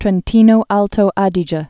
(trĕn-tēnō-ältōdē-jĕ)